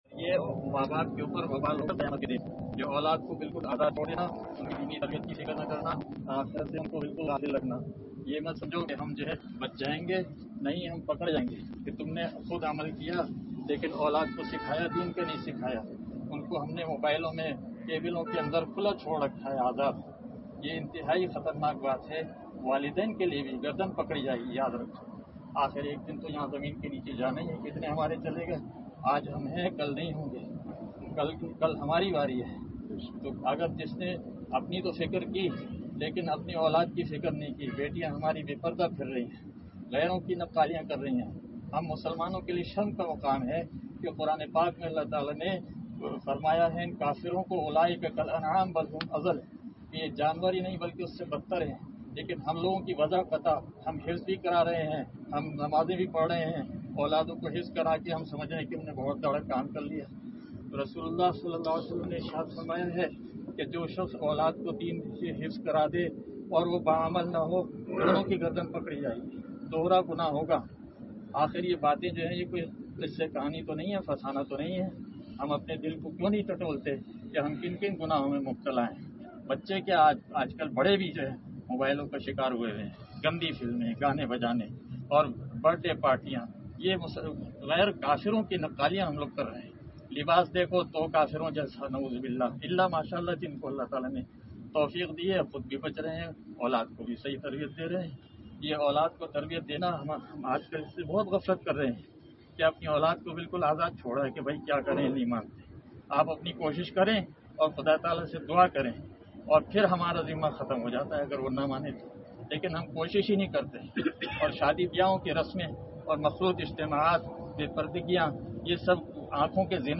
ایک تدفین کے موقع پر نصیحت